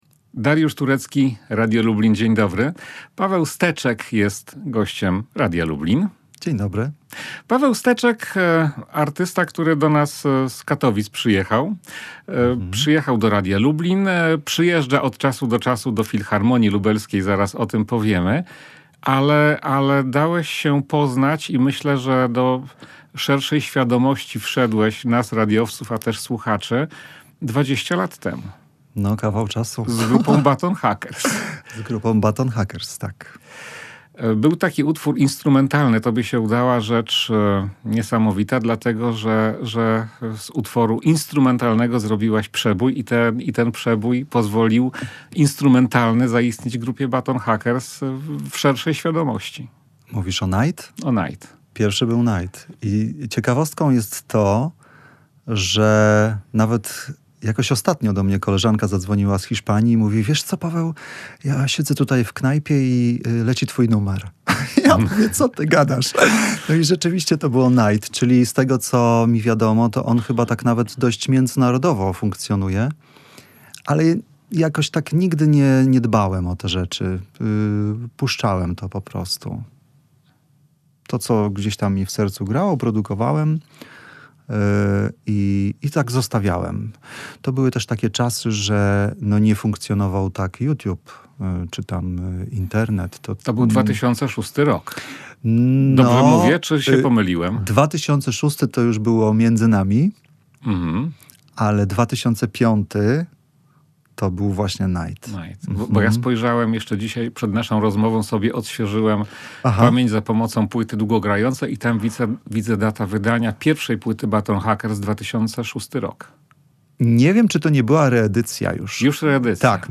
Dzięki uprzejmości naszego gościa, mamy możliwość wysłuchania niektórych kompozycji z tego projektu na radiowej antenie.